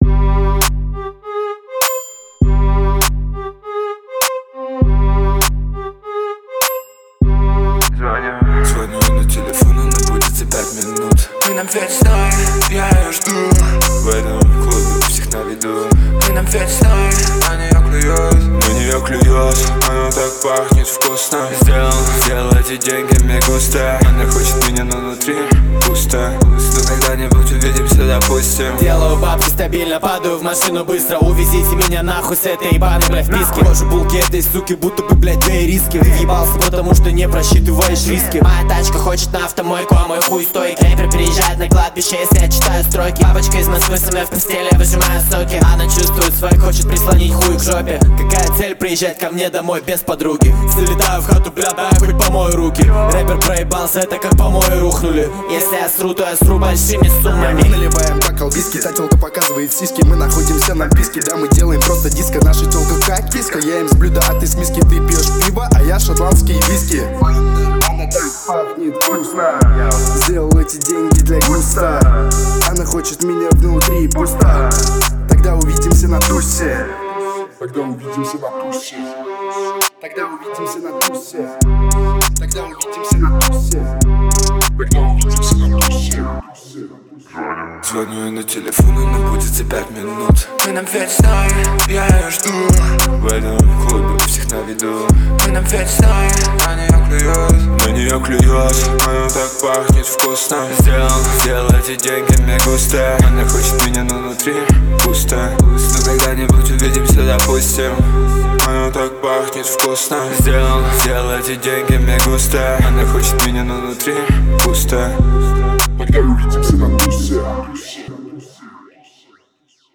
работающий в жанрах реп и хип-хоп.